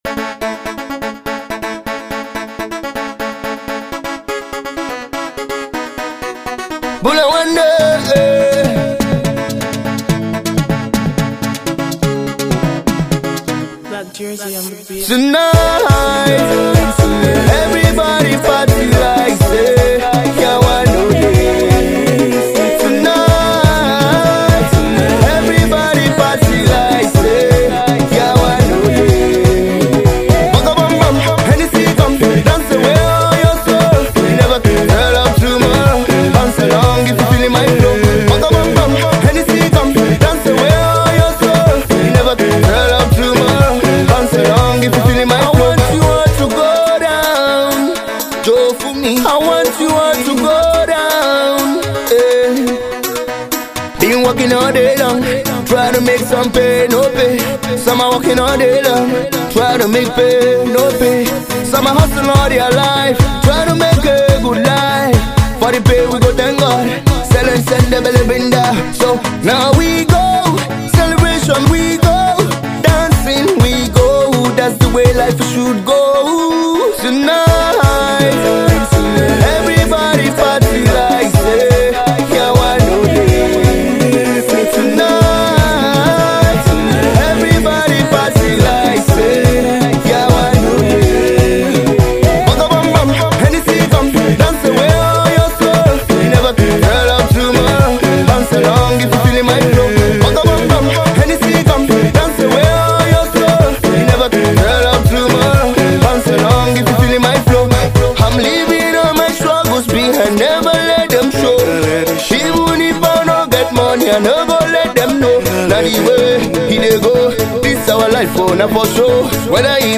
Club friendly beats on this new joint